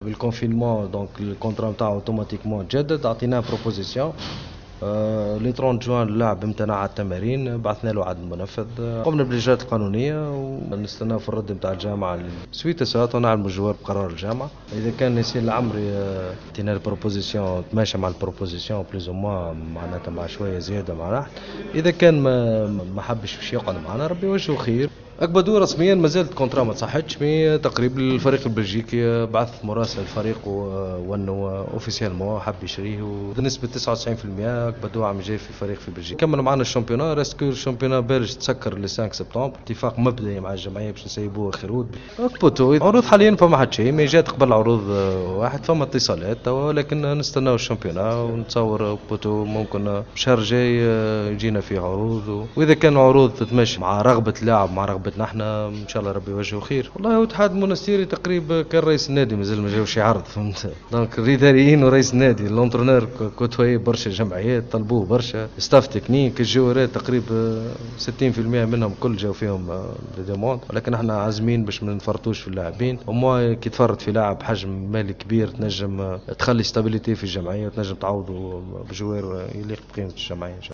خلال الندوة الصحفة التي عقدها اليوم عن وضعية بعض اللاعبين في الاتحاد